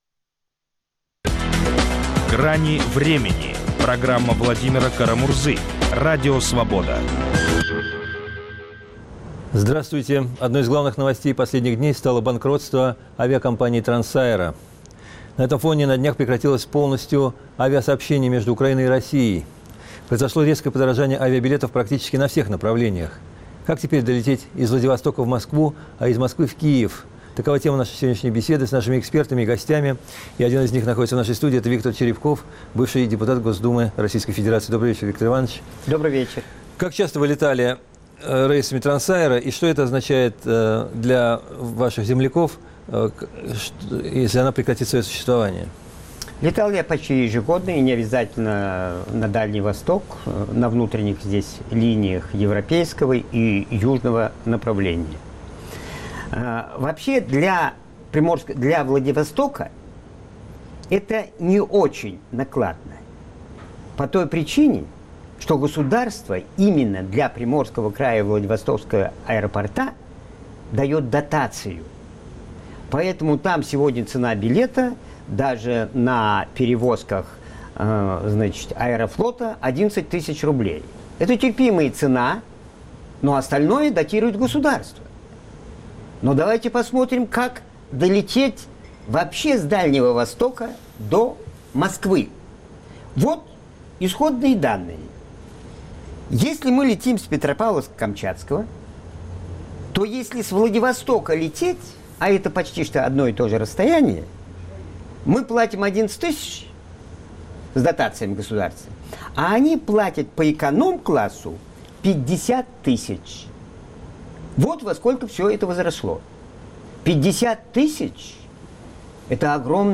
Банкротство "Трансаэро", прекращение авиасообщения между Украиной и Россией, резкое подорожание билетов практически на всех направлениях - куда бедному авиапассажиру податься? как долететь из Владивостока в Москву, а из Москвы в Киев? - спорят политики Иван Винник (Киев), Александр Старовойтов, Виктор Черепков.